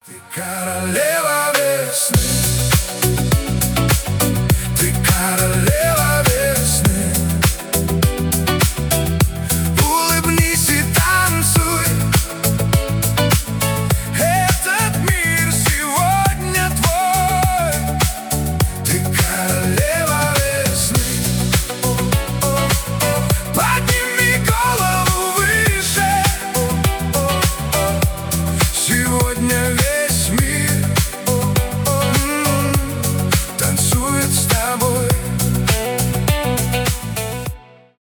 нейросеть , поп